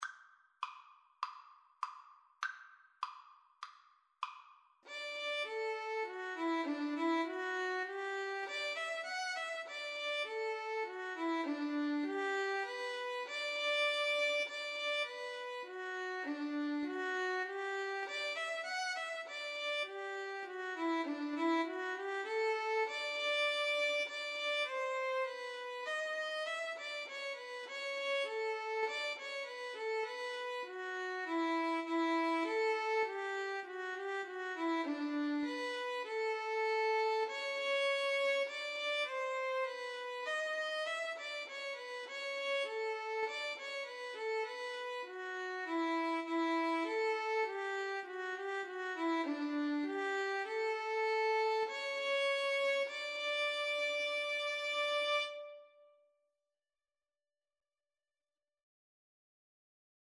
Christmas
4/4 (View more 4/4 Music)